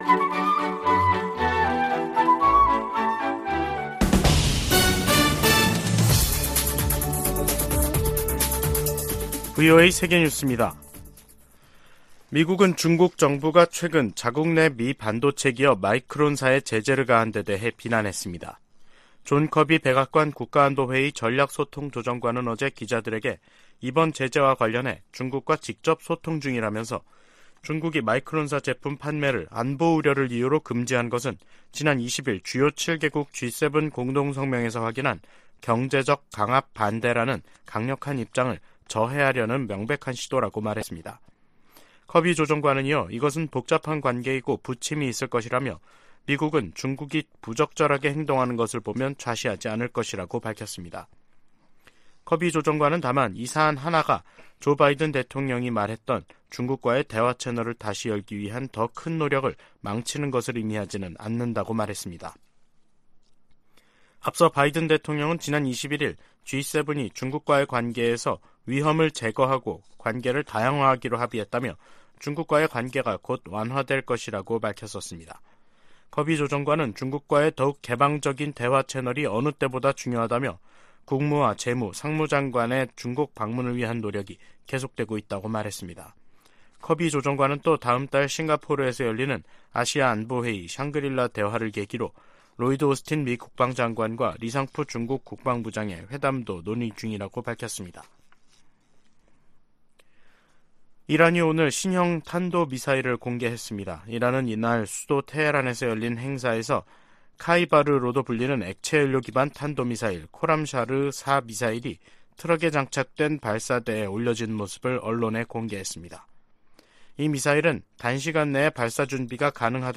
VOA 한국어 간판 뉴스 프로그램 '뉴스 투데이', 2023년 5월 25일 2부 방송입니다. 조 바이든 미국 대통령이 최근 미한일 정상회담에서 한일 정상의 관계 개선 노력을 높이 평가했다고 백악관 고위 관리가 밝혔습니다. 미군과 한국군이 25일부터 한반도 휴전선 인근 지역에서 역대 최대 규모 화력격멸훈련에 돌입했습니다. 북한 등 적국들이 미국 첨단 기술 획득을 시도하고 있다고 미 국무부 차관보가 밝혔습니다.